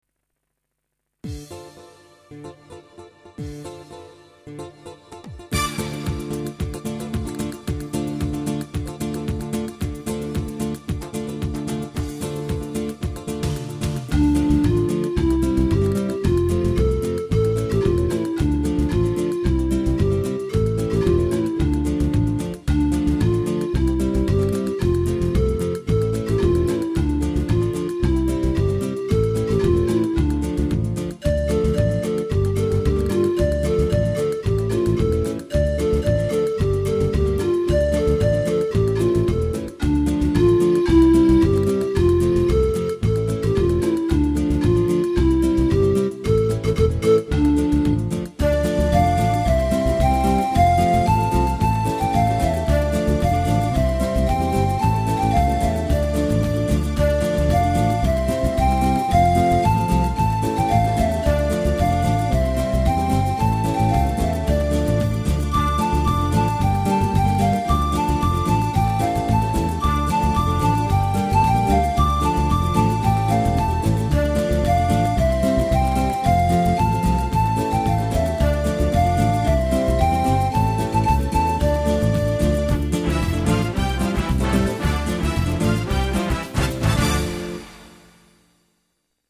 Basi musicali originali